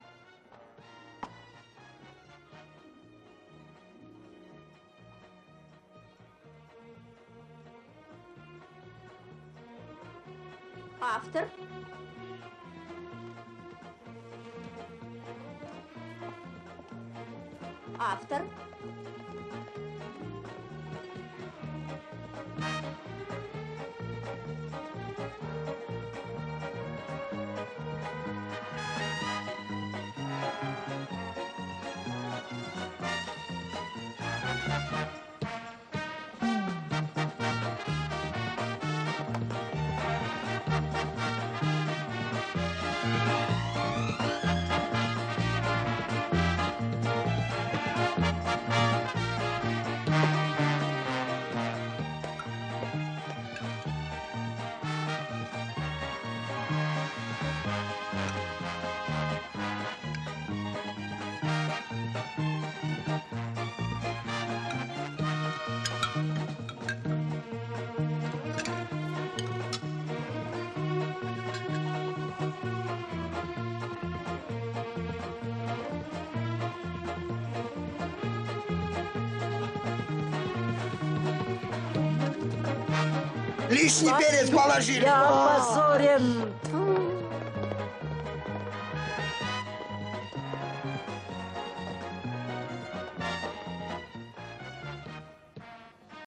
Качество слабое, но расслышать можно.